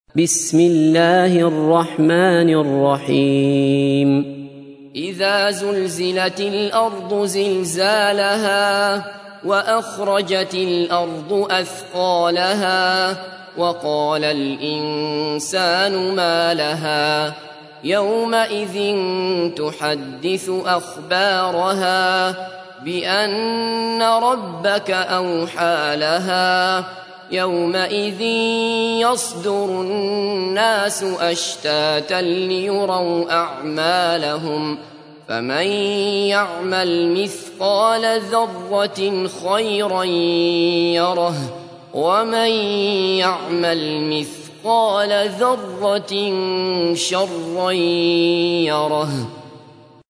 تحميل : 99. سورة الزلزلة / القارئ عبد الله بصفر / القرآن الكريم / موقع يا حسين